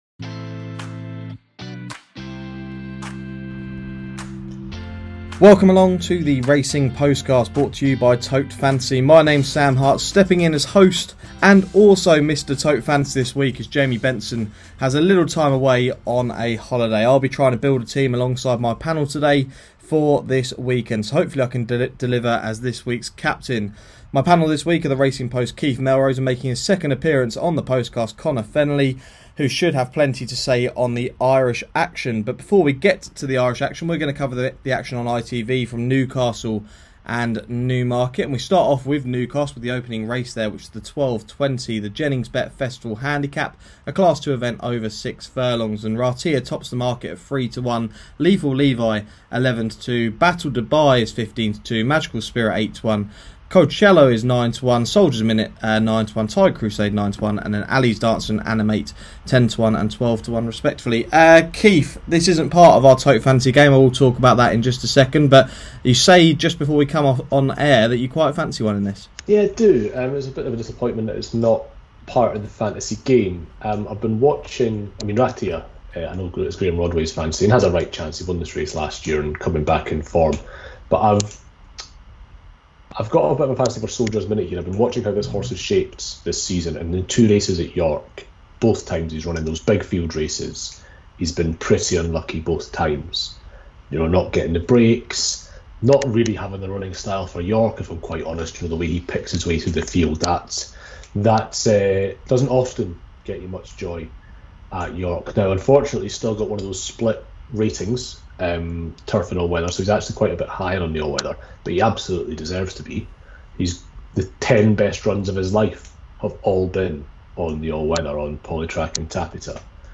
To finish, the panel give their thoughts on the action from the Curragh, where the three-day Irish Derby meeting takes place.
As always to end the show, the Postcast team give their best bets and select their Tote Fantasy stable.